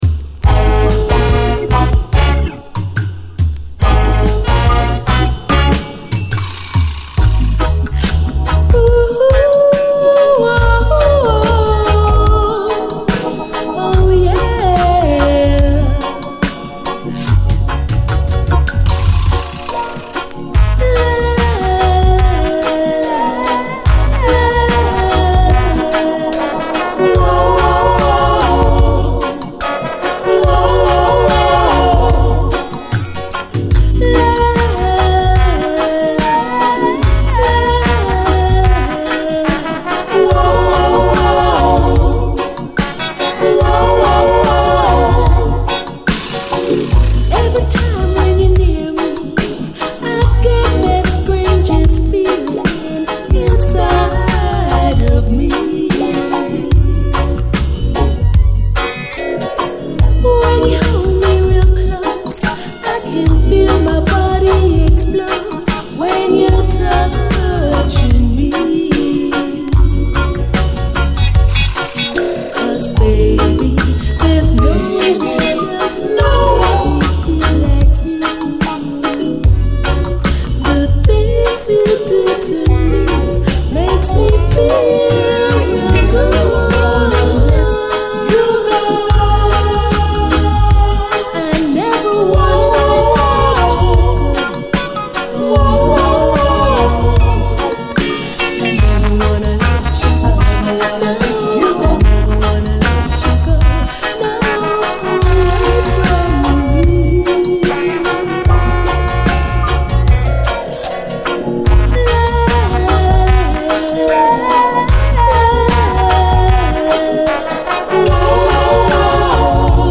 REGGAE
吐息のようなコーラスにトロけます。ダブ・ヴァージョンも収録。